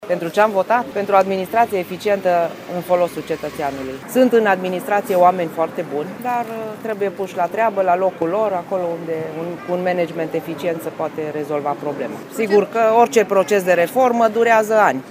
Şi prefectul judeţului Caraş-Severin, Florenţa Albu a votat astăzi la secţia NR. 18 de la Universitatea Eftimie Murgu din Reşiţa: